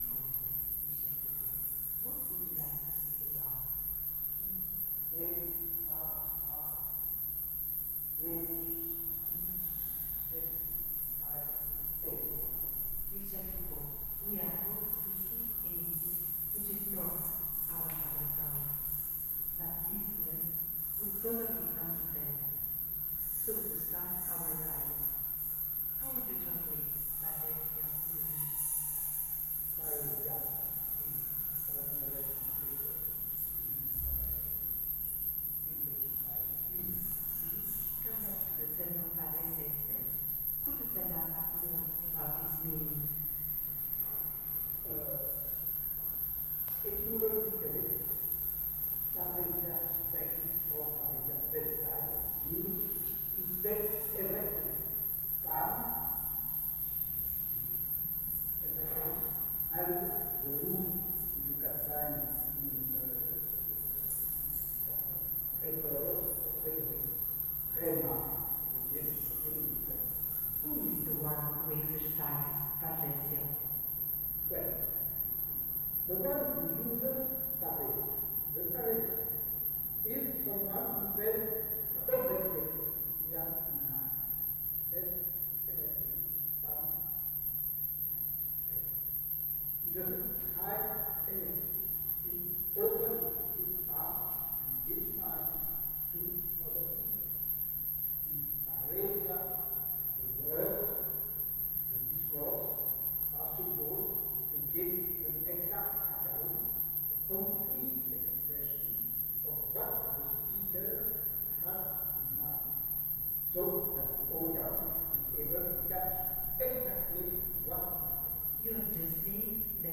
we thought to enact a form of coming together, where each can express their individual voice while also contribute to the sharing of ideas, searching for resonances and amplifications ( room recording )